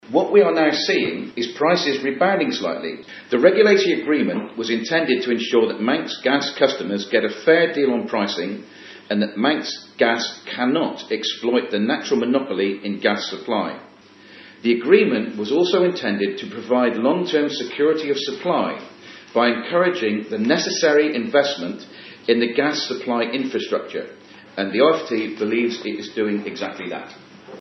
In the House of Keys yesterday, Chairman of the Office of Fair Trading Martyn Perkins claimed the agreement between the company and the government to cap profits is the best  way of managing the supply.